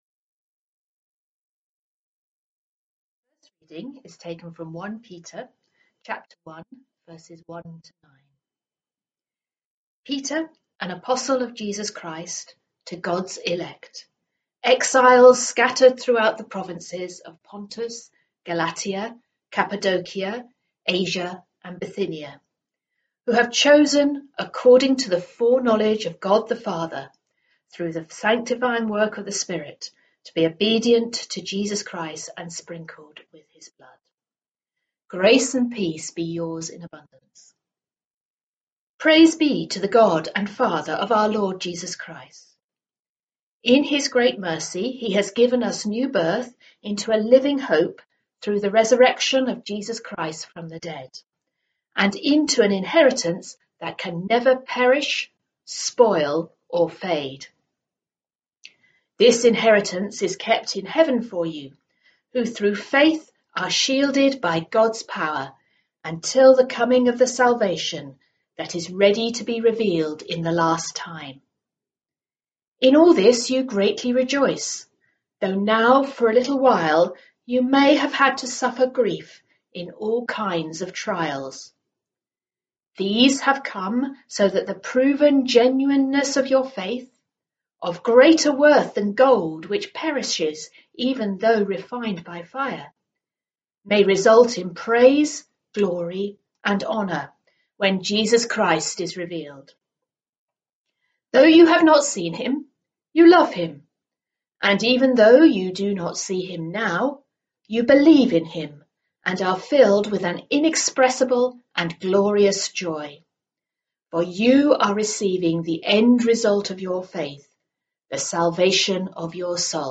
Theme: A Living Hope Reading, Sermon, Hymn